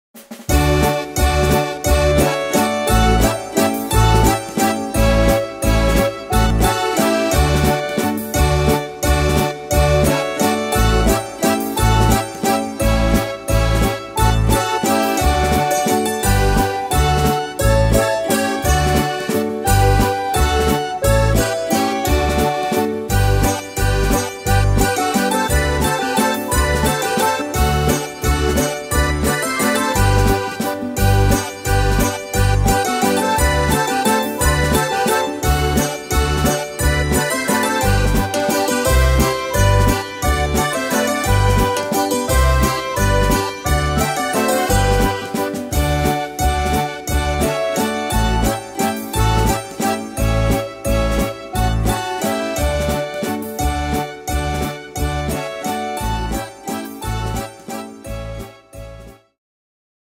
Tempo: 175 / Tonart: D-Dur